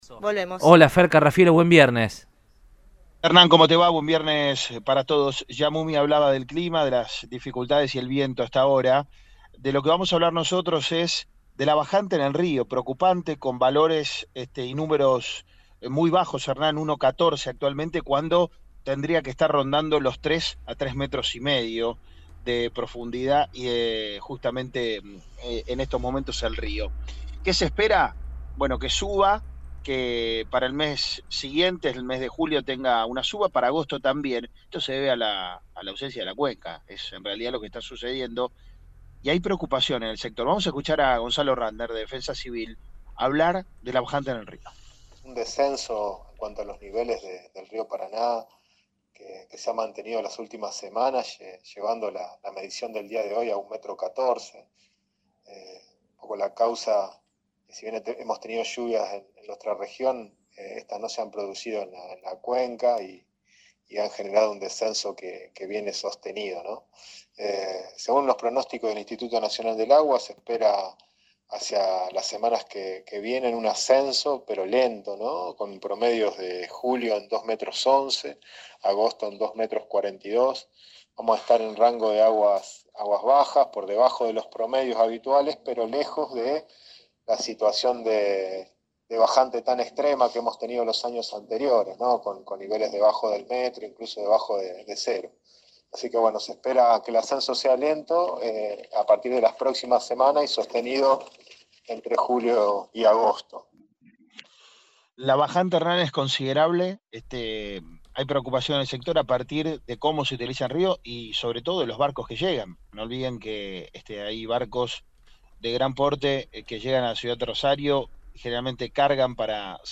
Gonzalo Ratner, titular del área de Defensa Civil de la Municipalidad de Rosario, dialogó sobre este tema con el móvil de Cadena 3 Rosario, en Radioinforme 3, y explicó: “El descenso en cuanto a los niveles se mantuvo en las últimas semanas, llevando la medición a 1.14. Si bien tuvimos lluvias, no se produjeron en la cuenca”.